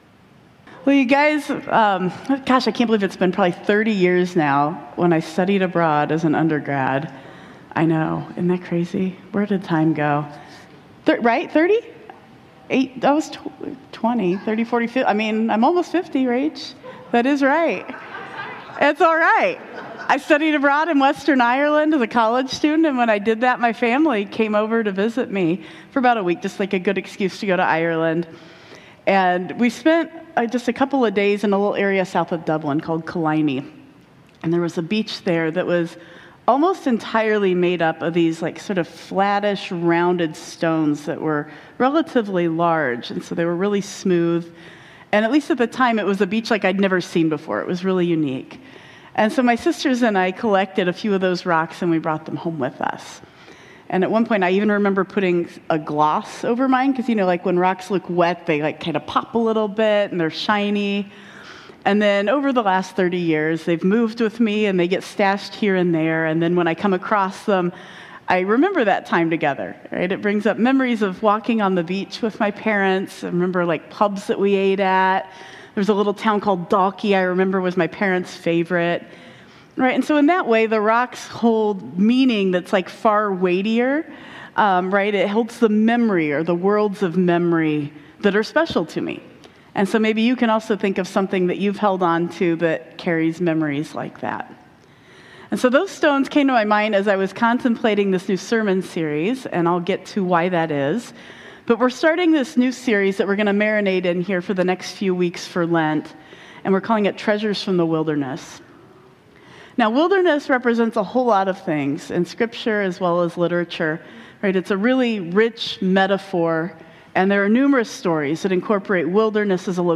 We open our new sermon series and talk about how remembering God’s faithfulness and other ways we get through hard times builds resilience.